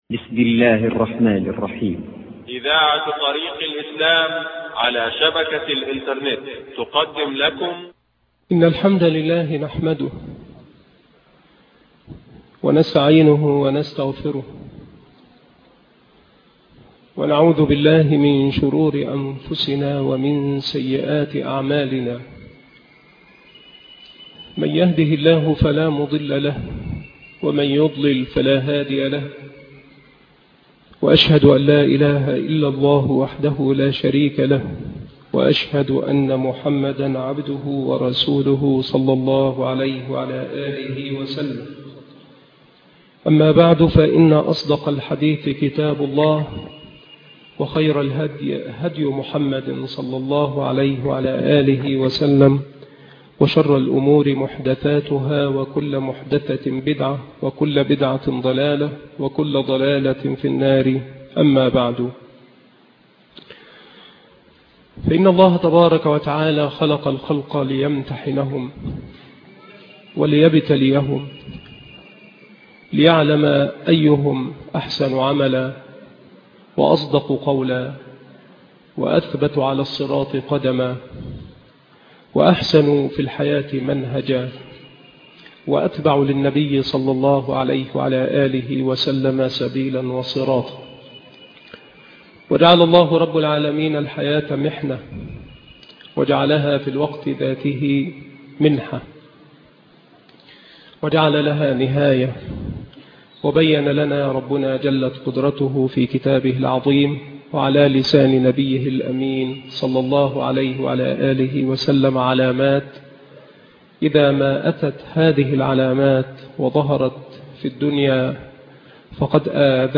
محاضرة الدخان وخروج يأجوج ومأجوج والدابة والنار التي تحشر الناس الشيخ محمد بن سعيد رسلان